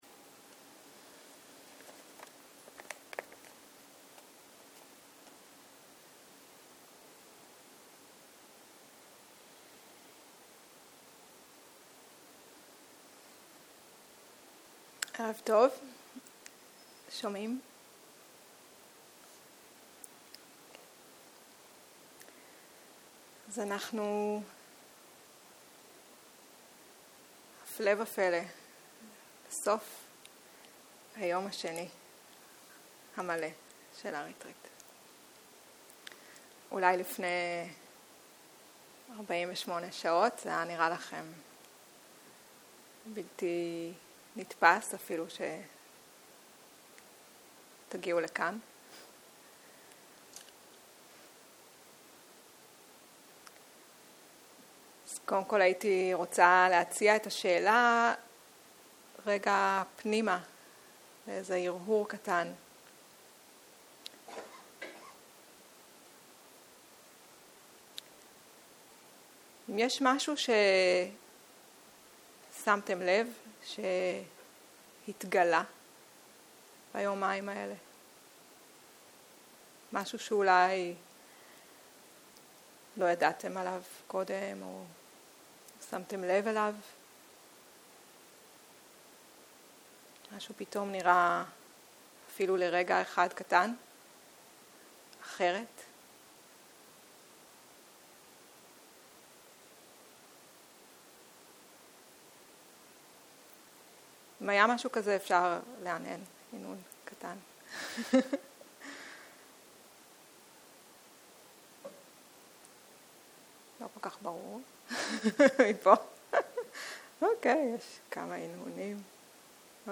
ערב - שיחת דהרמה - על גדת השיטפון - שיחה 7